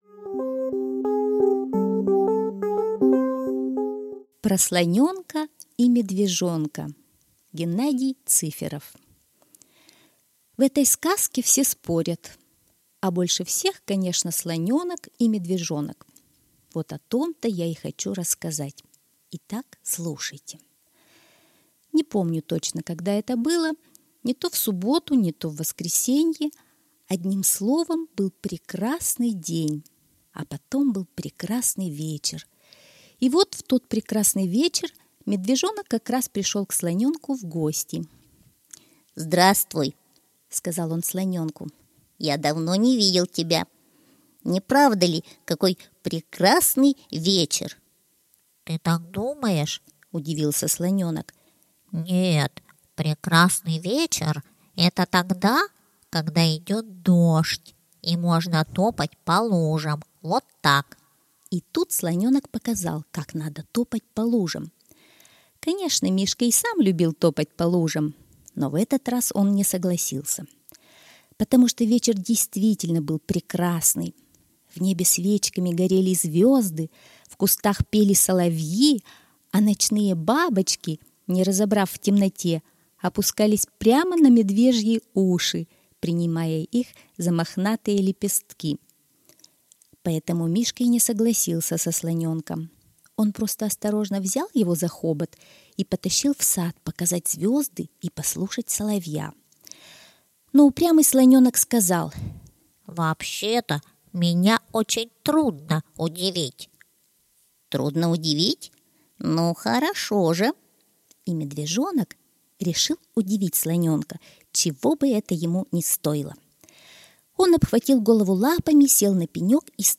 Про слонёнка и медвежонка — аудиосказка Цыферова Г.М. История про медвежонка, который очень хотел удивить своего друга слоненка.